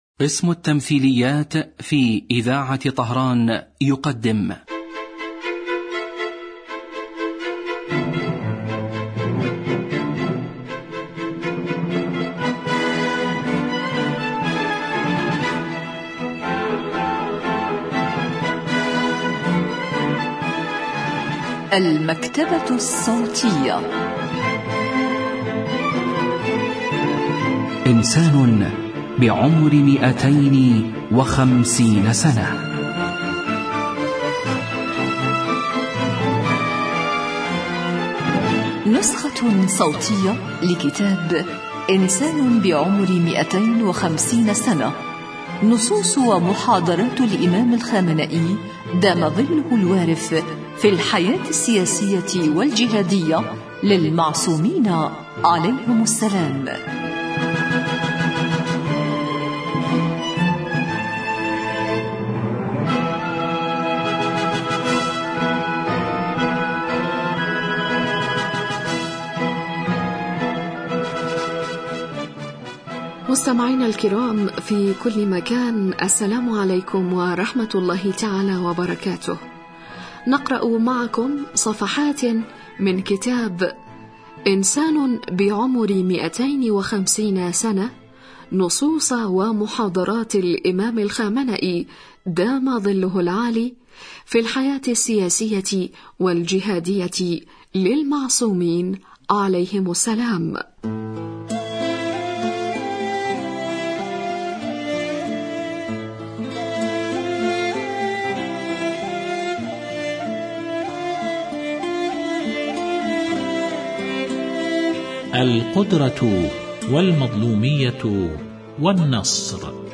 الكتاب الصوتي